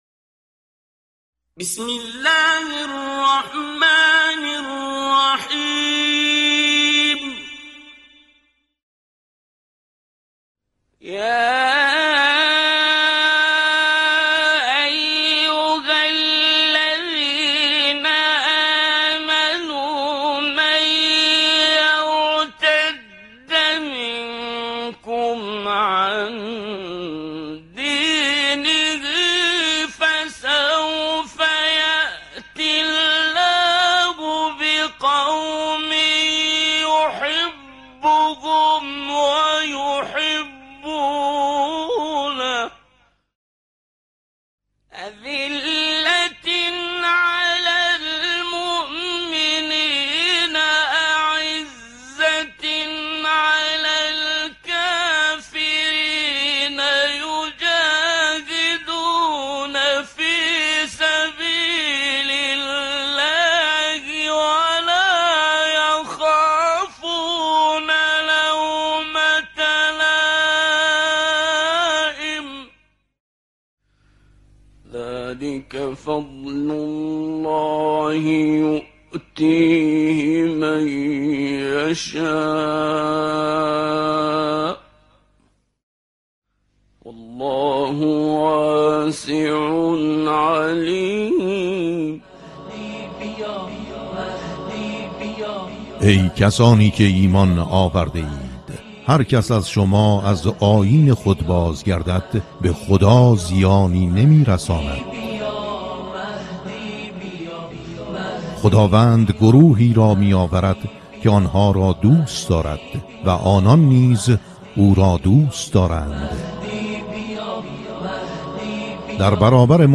همراه با تفسیر کوتاه این آیه می‌شنوید. در سومین قسمت آیه 54 سوره مبارکه مائده ارائه می‌شود.